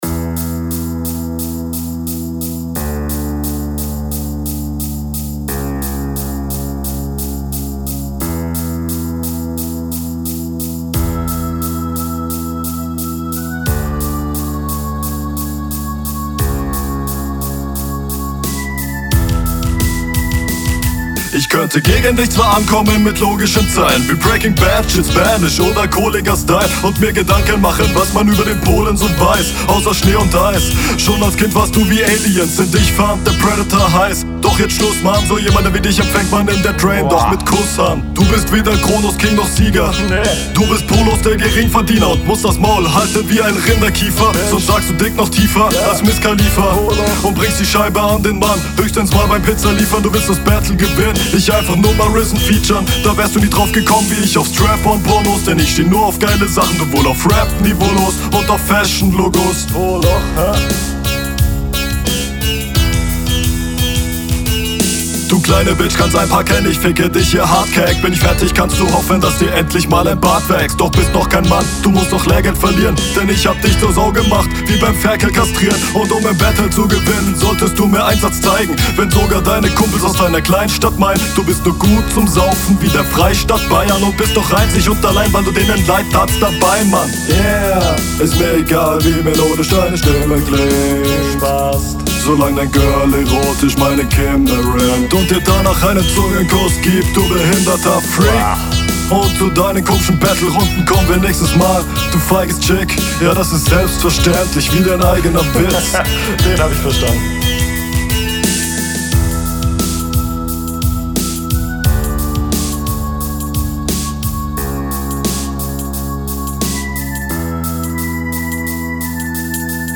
Flow: Du rappst sehr solide auf dem Beat und betonst auch angenehm.